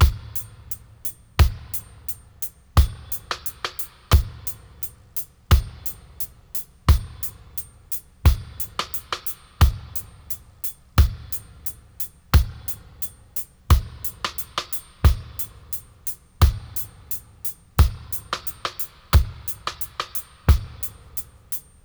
88-FX-01.wav